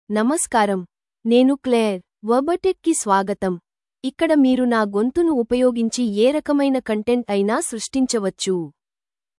Claire — Female Telugu AI voice
Claire is a female AI voice for Telugu (India).
Voice sample
Listen to Claire's female Telugu voice.
Female
Claire delivers clear pronunciation with authentic India Telugu intonation, making your content sound professionally produced.